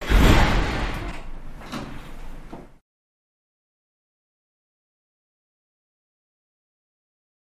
Mountain Cable Car, Interior Landing Klunk